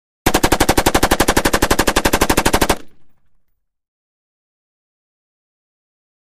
Mini-14 Automatic: Single Burst; One, Lengthy, Rapid Fire Burst. Heavy Shots With Shells Falling At End Of Burst. No Echo. Close Up Perspective. Gunshots.